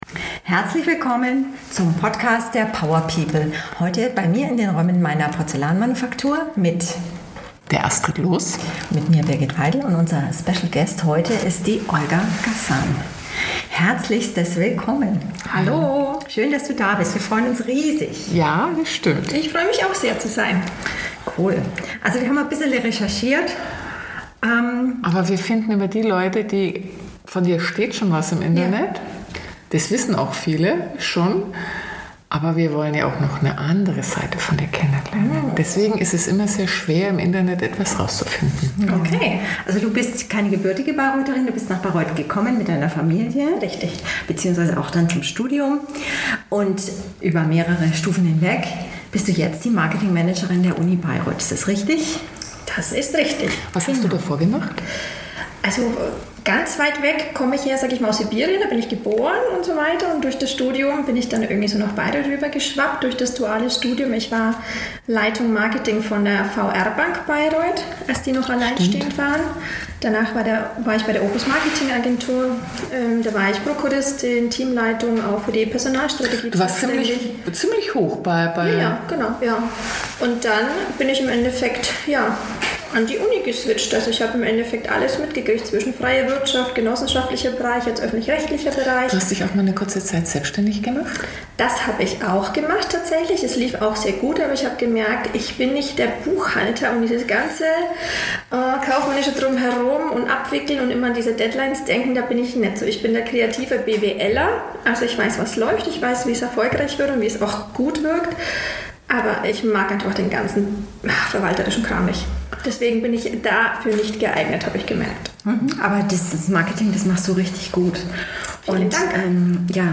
Ein Gespräch über kulturelle Brücken, kreative Energie und die Kraft gemeinsamer Erlebnisse.